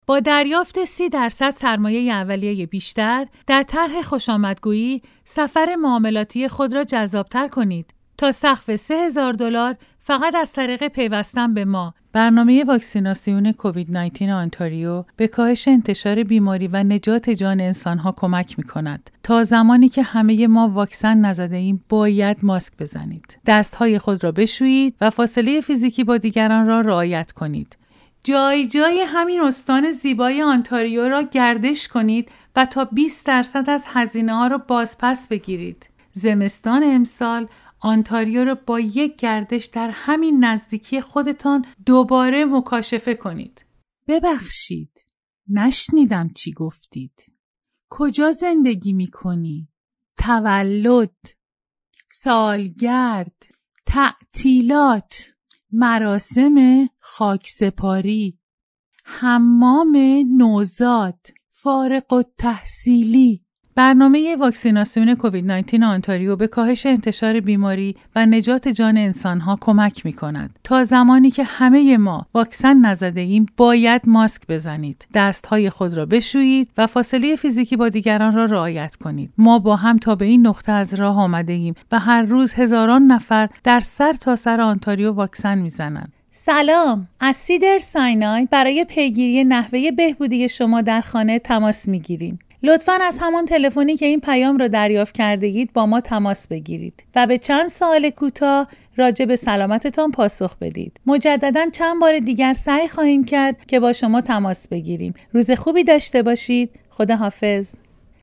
Female
Adult
E-learning